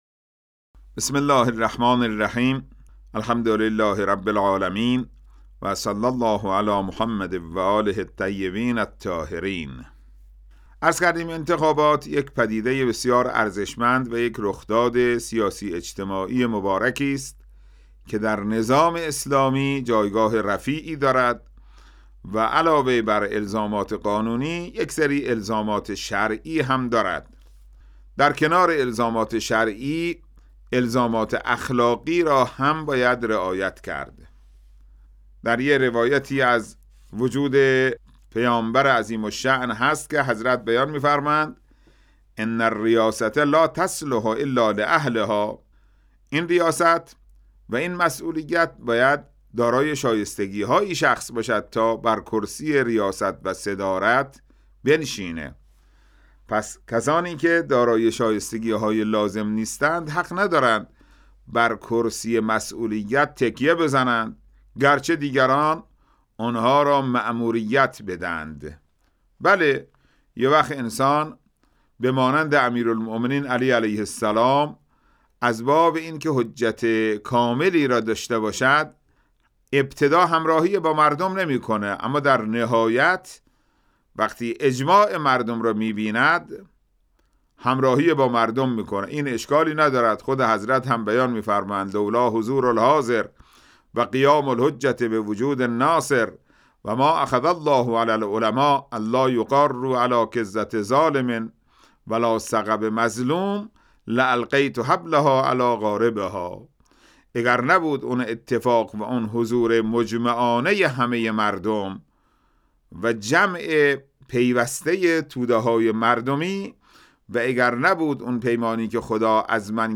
امام‌جمعه بخش مرکزی یزد با اشاره به اینکه انتخابات یک پدیده ارزشمند، رخداد سیاسی و اجتماعی مبارکی است که در نظام اسلامی جایگاه رفیعی دارد گفت: انتخابات علاوه بر الزامات قانونی، الزامات شرعی هم دارد که در کنار آن باید الزامات اخلاقی را نیز رعایت کرد.